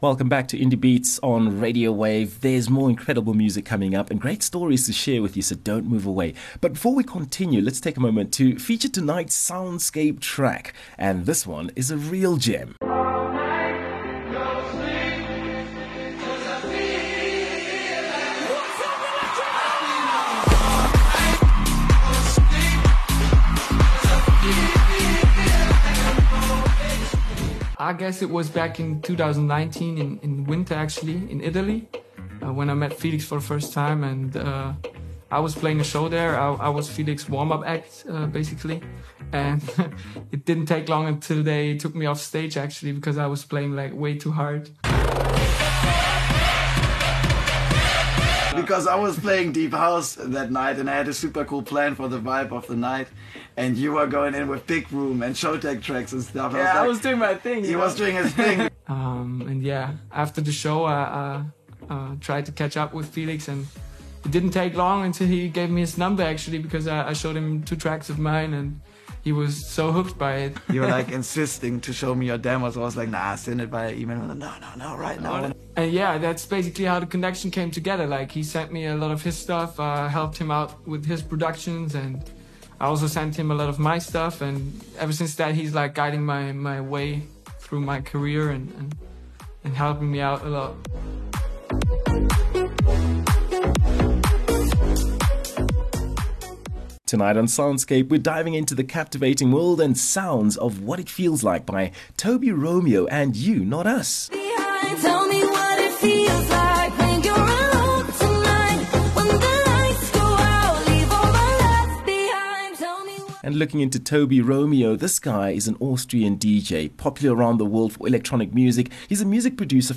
Get ready to immerse yourself in the pulsating rhythms and experience the euphoria of the dancefloor.